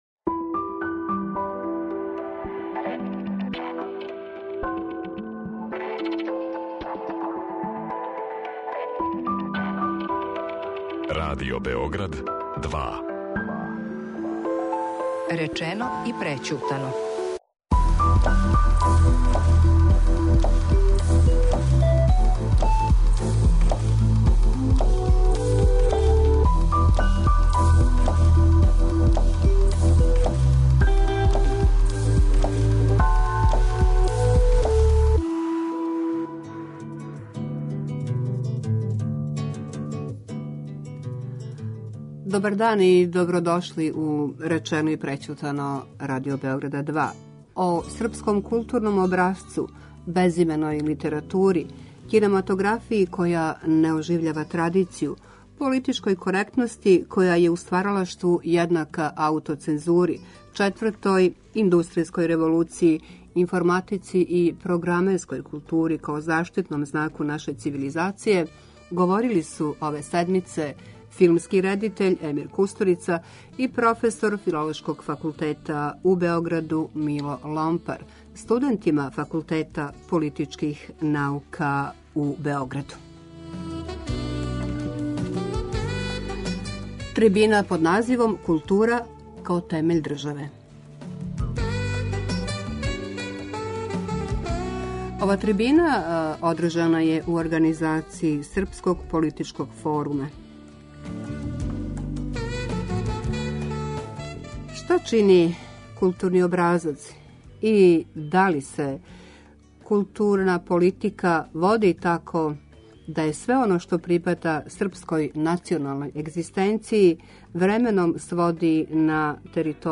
О српском културном образцу, безименој литератури, кинематографији која не оживљава традицују, политичкој коректности која је у стваралаштву једнака аутоцензури, четвртој индустријској револуцији, информатици и програмерској култури као заштитном знаку наше цивилизације говорили су ове седмице филмски редитељ Емир Кустурица и професор Филолошког факултета Мило Ломпар, студентима Факултета политичких наука у Београду.